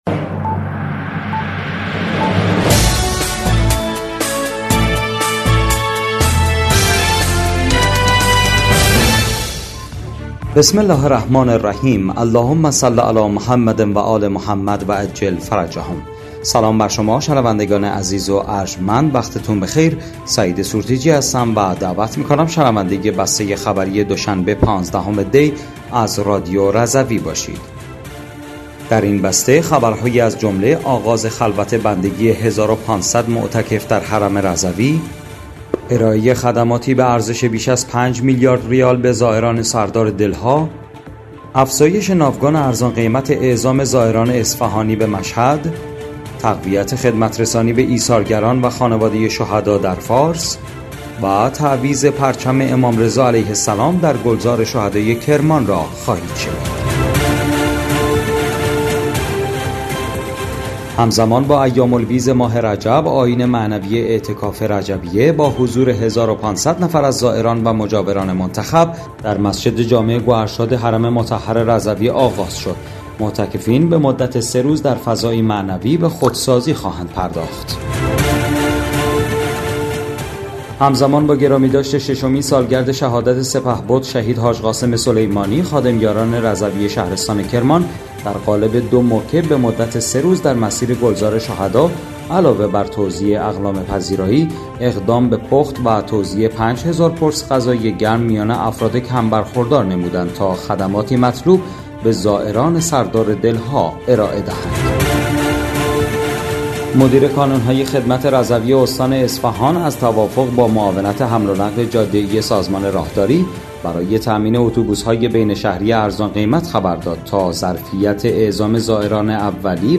بسته خبری ۱۵ دی ۱۴۰۴ رادیو رضوی؛